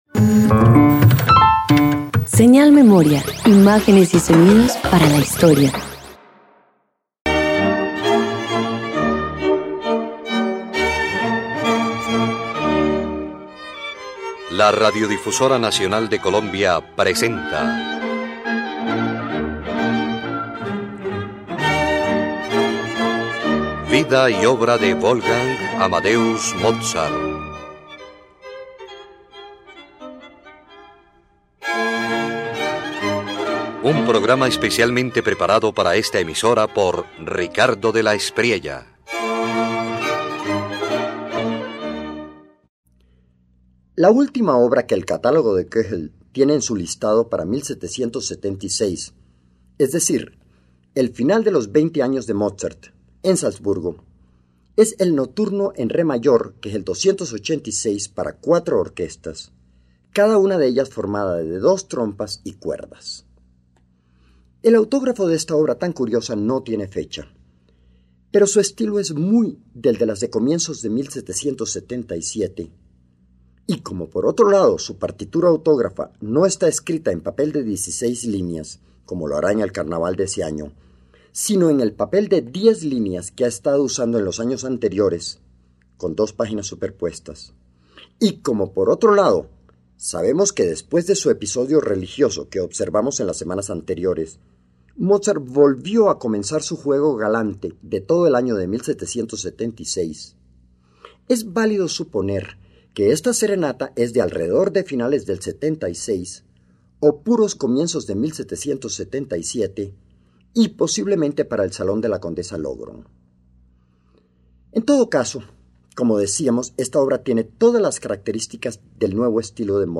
Mozart cierra 1776 con el Nocturno en re mayor, K286, una obra para cuatro orquestas que juegan con ecos y repeticiones. Entre humor y elegancia, el joven compositor experimenta con las trompas y muestra su creciente madurez musical.